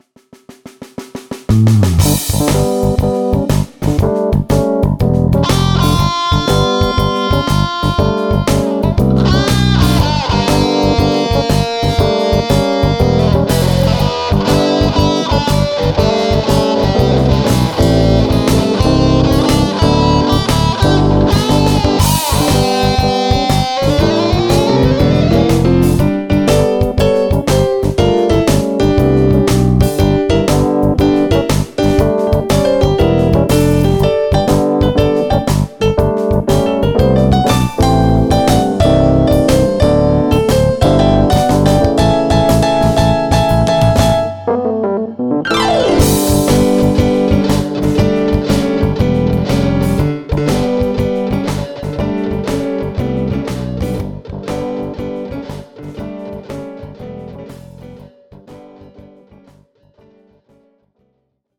あわせて前々から試してみたかったRhodes のライブラリ、Rhodes Suitcaseを買いました。
なかなか太い音がして漫然とコードやリフを鳴らしているだけでも気持ちいいです。
コロコロいう離鍵ノイズも再現されててなかなか弾いてて楽しい。
ブルースっつーものを全然わかってないのに変形ブルース風な曲なんて無謀ですね。